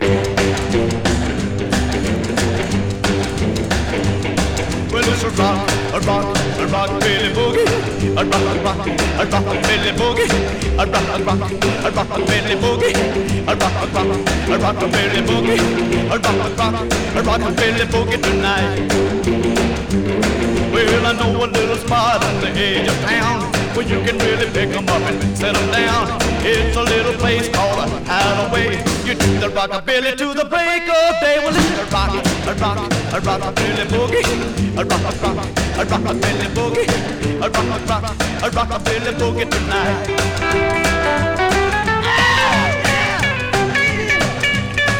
Rock, Rockabilly　Italy　12inchレコード　33rpm　Mono